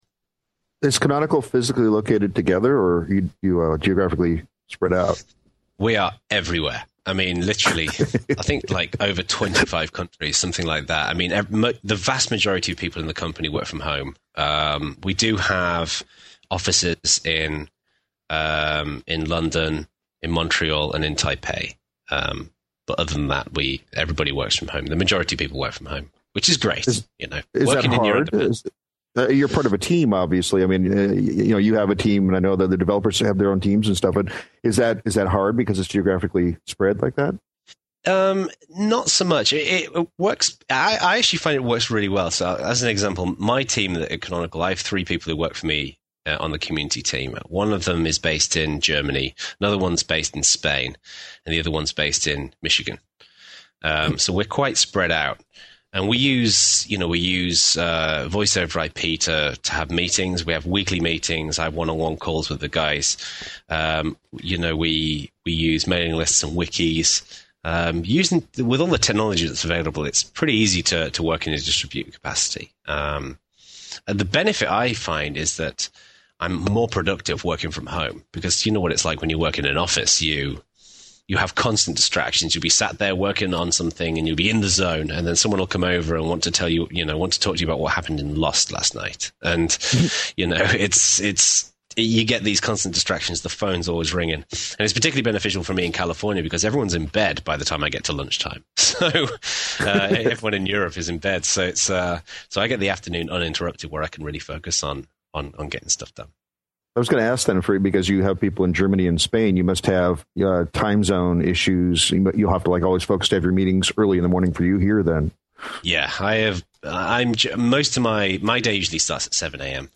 This is an excerpt (running time: 3:01) from an interview (running time: 49:10) about the latest Ubuntu Linux distribution.